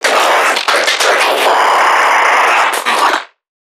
NPC_Creatures_Vocalisations_Infected [5].wav